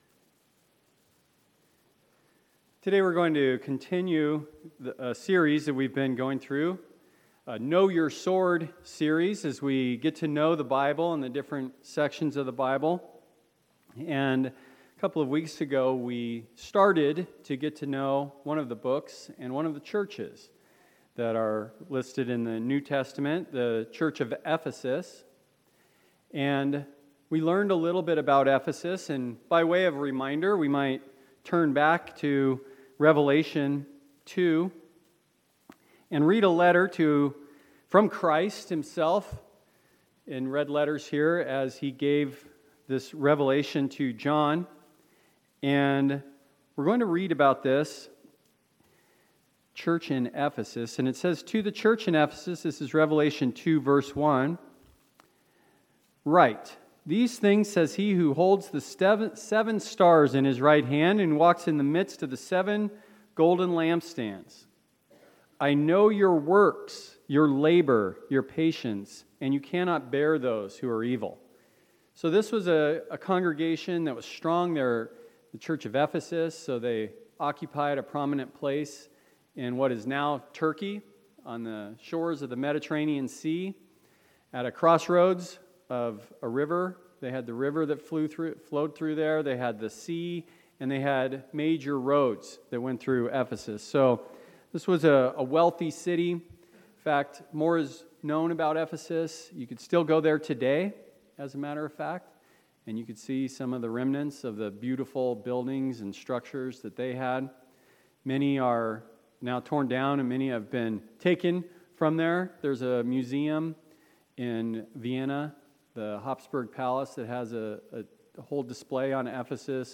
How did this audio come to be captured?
Given in Phoenix Northwest, AZ